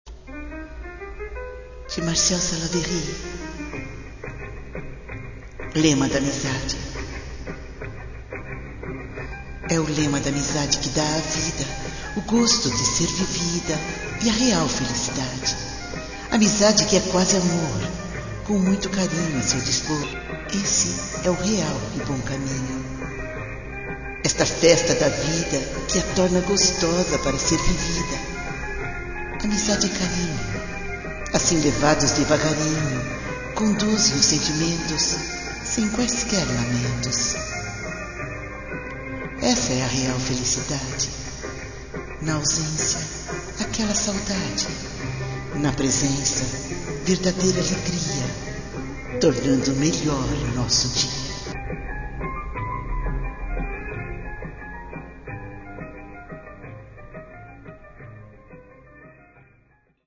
E com sua doce voz,